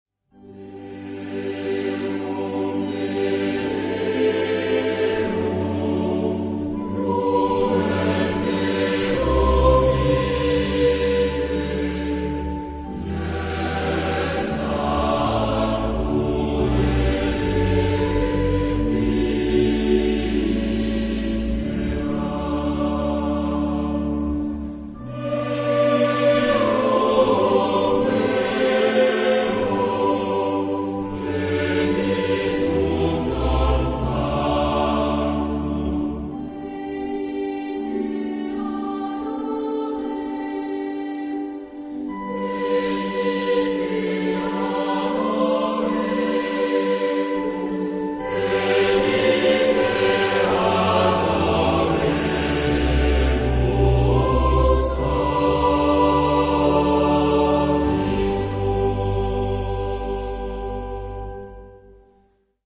Genre-Style-Form: Sacred ; Christmas carol
Type of Choir: SATB  (4 mixed voices )
Instruments: Organ (1)
Tonality: G major